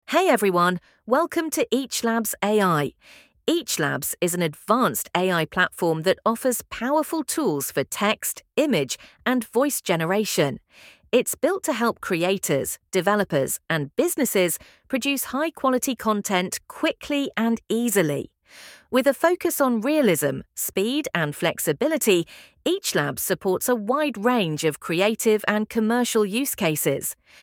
ElevenLabs | Text to Speech - AI Model | Harmony AI
Metinden doğal, anlamlı konuşma üretmek için çok dilli sesler, hassas vurgu kontrolü ve profesyonel anlatım ve seslendirmeler için gerçek zamanlı sentez kullanın.
elevenlabs-t2s-output.mp3